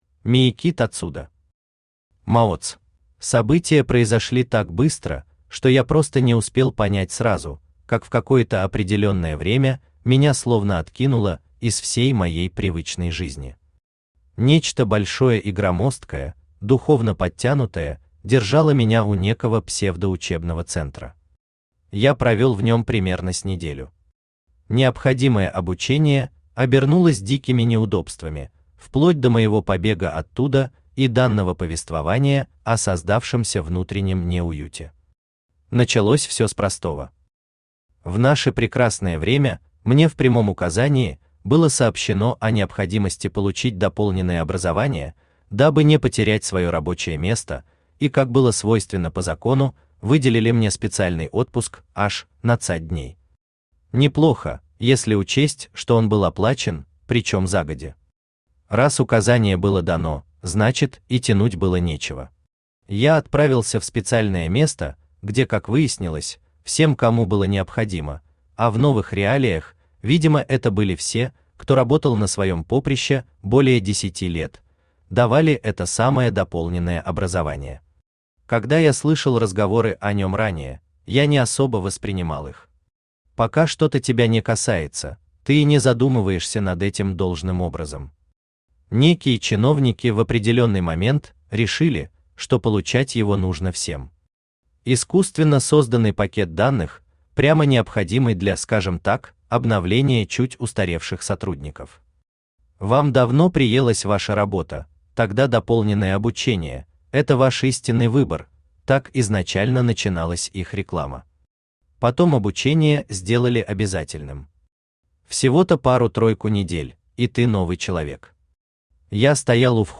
Aудиокнига МАОЦ Автор Мияки Тацудо Читает аудиокнигу Авточтец ЛитРес. Прослушать и бесплатно скачать фрагмент аудиокниги